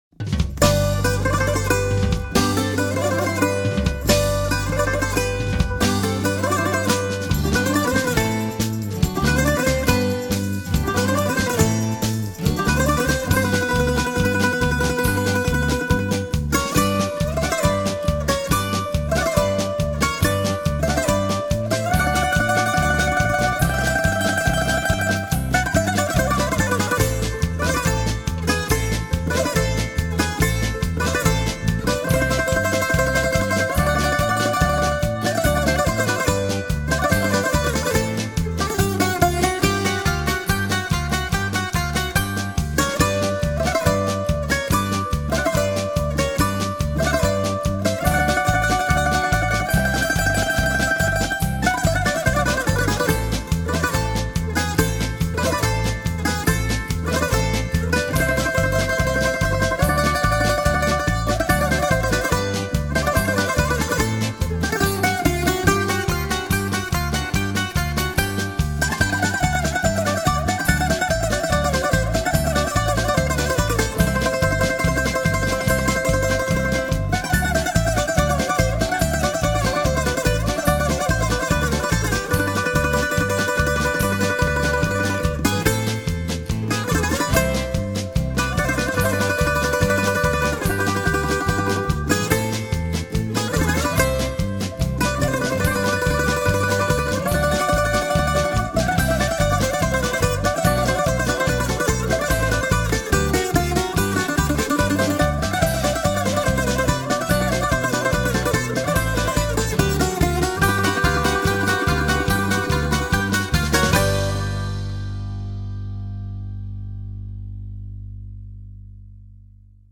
music_grec3.wma